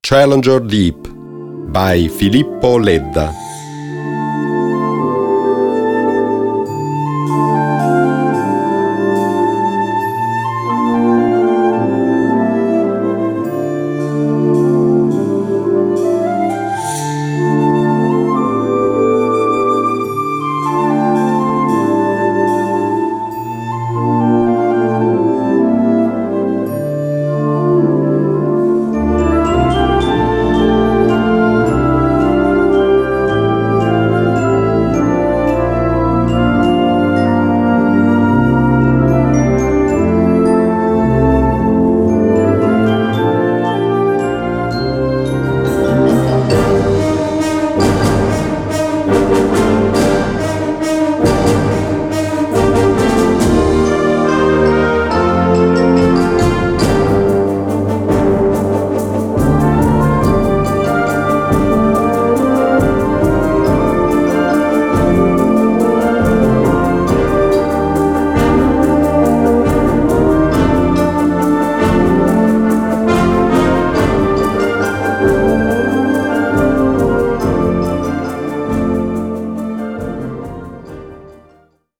Kategorie Blasorchester/HaFaBra
Unterkategorie Konzertmusik
Besetzung Ha (Blasorchester)